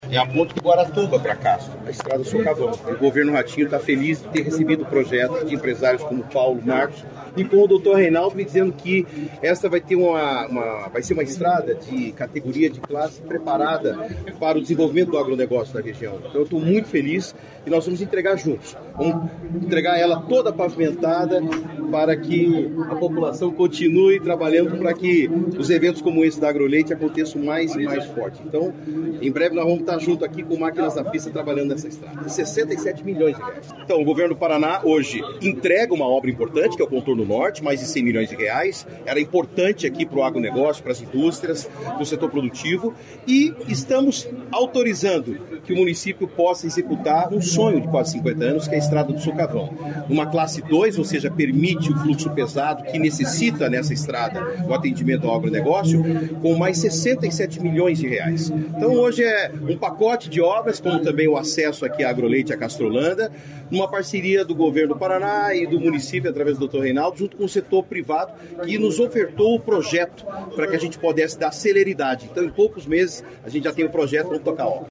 Sonora do secretário Estadual de Infraestrutura e Logística, Sandro Alex, sobre o pacote de investimentos em infraestrutura em Castro